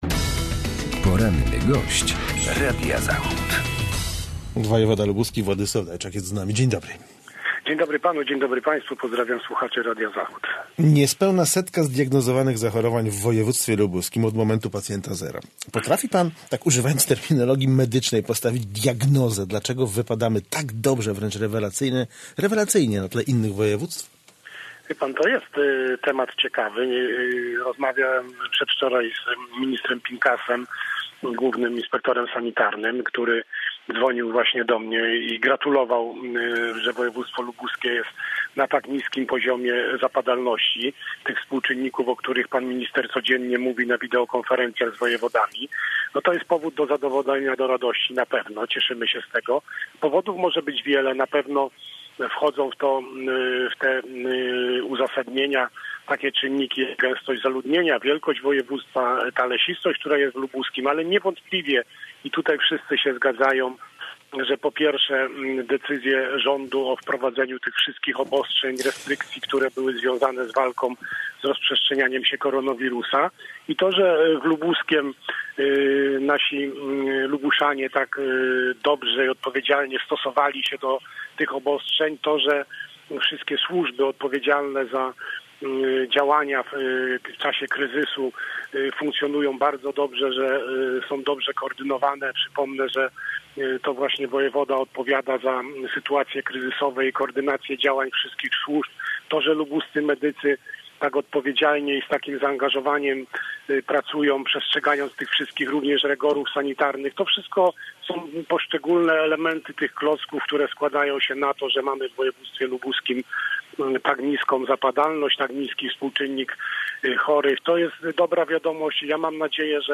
Z wojewodą lubuskim rozmawia